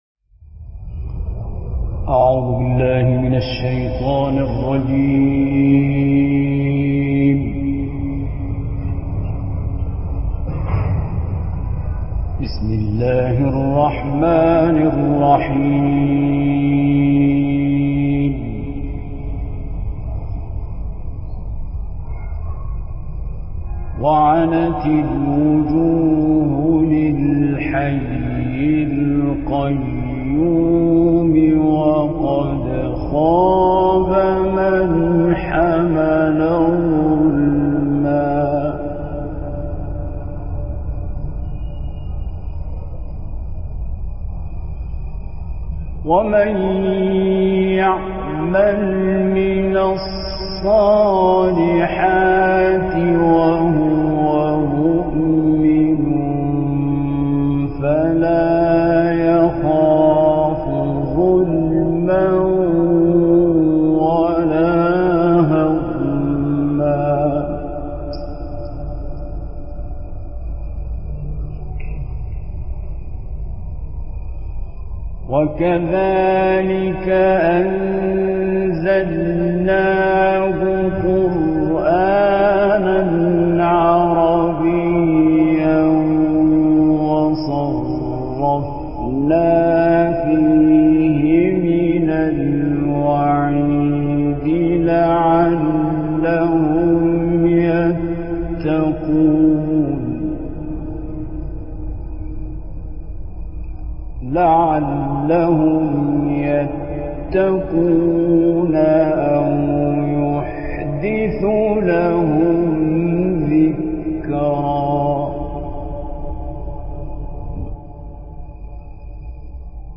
استاد عبدالباسط عبدالصمد آیات 11 تا 122 از سوره «طه» را با صوتی شنیدنی تلاوت کرده‌اند که تقدیم مخاطبان ایکنا می‌شود.